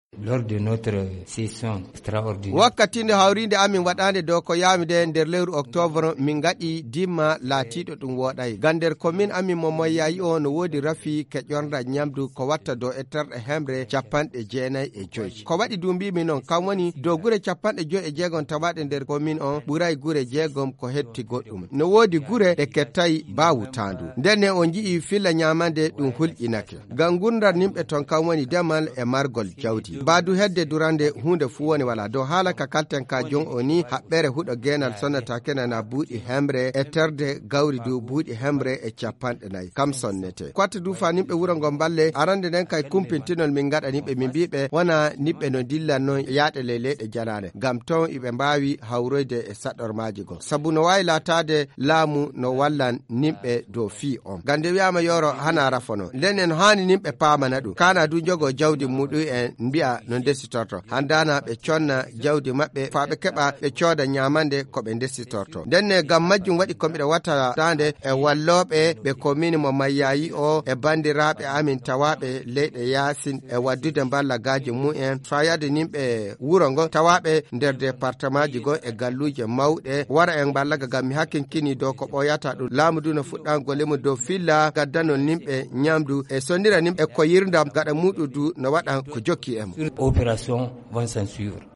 Pour plus de précisons, écoutons le maire de la commune urbaine de Mayahi Abdou Mai-Guero au micro de notre correspondant